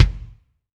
ROOM BD 2.wav